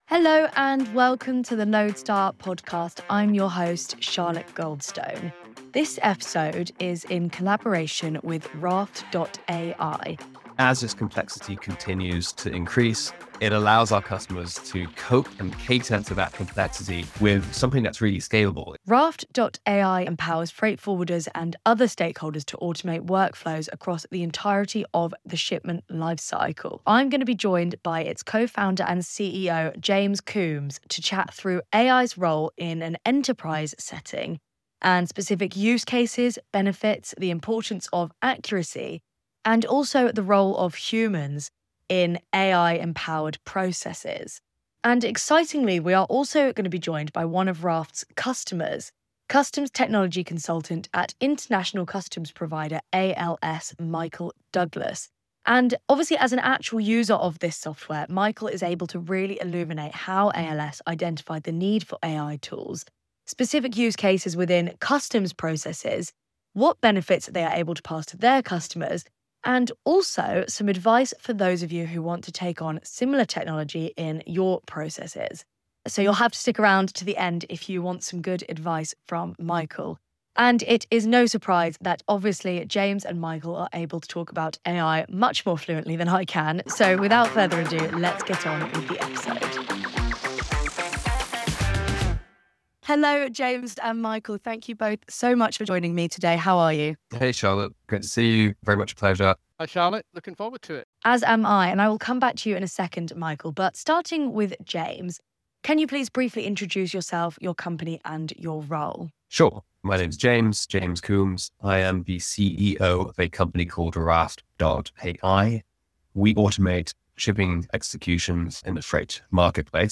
The conversation offers a rare, behind-the-scenes look at AI in action.